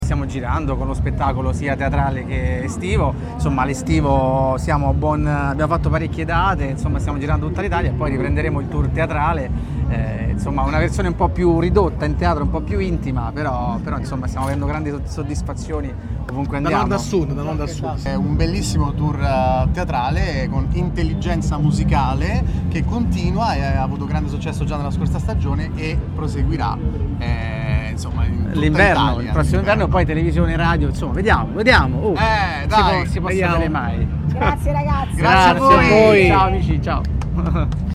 Al termine dello spettacolo, i tre fratelli hanno raccontato le loro esperienze di tour in giro per l’Italia: